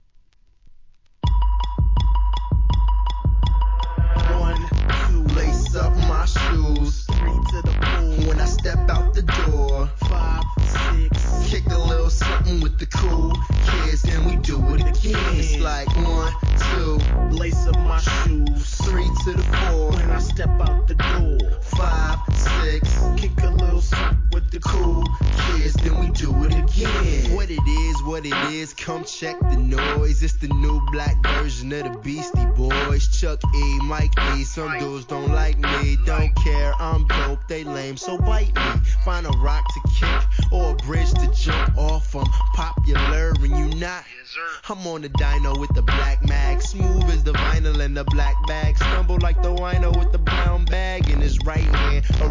HIP HOP/R&B
OLD SCHOOLな軸を持ちつつ新鮮なサウンド・プロダクションで魅了する1stアルバム!!